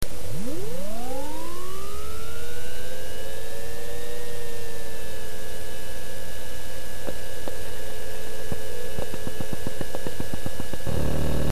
再々度騒音測定（あくまでも素人計測だってば）
※あまりにも録音時の音量が小さいので、ソフト側で音量を２０倍に上げてます。
＜アルミのお弁当箱（前後スポンジ変更後＋スポンジ穴埋め＋平らなフタ使用）＞
ちょっとは静音効果があったような感じですが…。
06-noize-test_seionbox-nks.wav